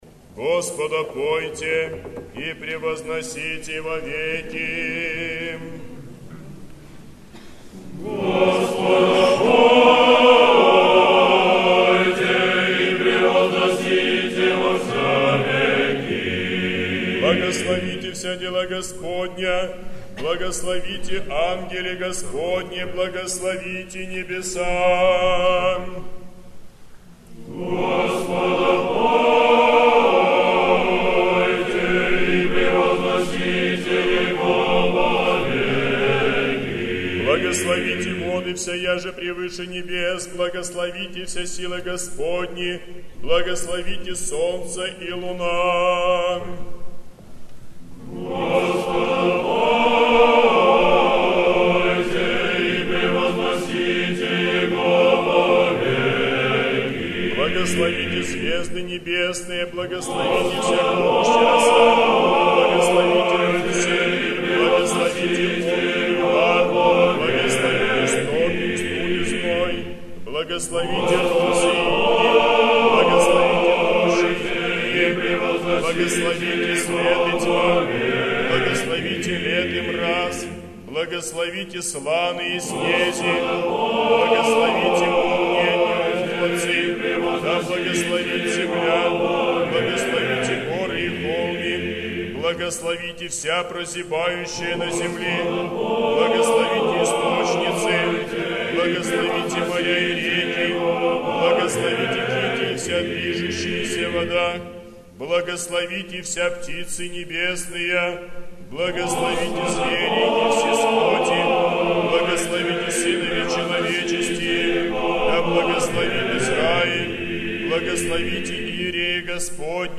Praise the Lord and exalt him above all for ever (refrain).